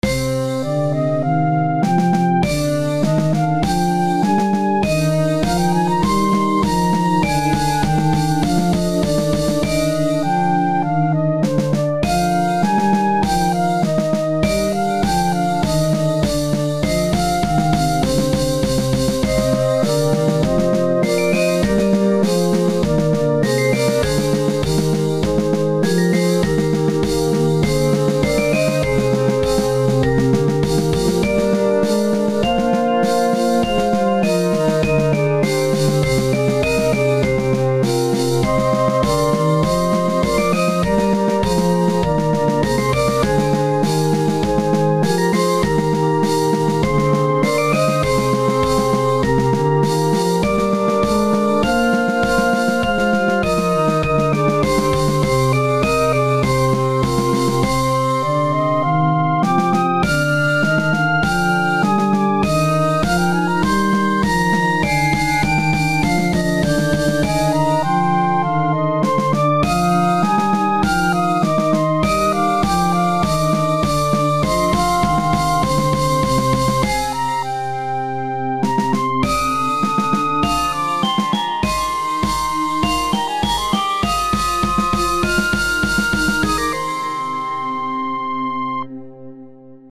Instrumentation: Percussion includes Snare drum, bass drum, and crash cymbals, then there are 2 Flute parts, 1 'Brass' part which was a somewhat changed French Horn thing, and also Bells.
Still no mastering done because I am still learning through all of that stuff and will hopefully learn it in time.
I know I need to make the percussion part a little less repetitive but for some reason I'm not doing too well in coming up with a fitting part for it on this song.
It's a cute little song, but the samples and synths really are a bit toony/cheesy. It would work as an old midi-type song, but if that wasn't your aim, try to make the sound a bit more interesting.
Sounds kinda like one of those old DOS/Nintendo/SEGA games.
project_1_flute_bells_and_brass_addition_617.mp3